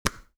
DelayClap.wav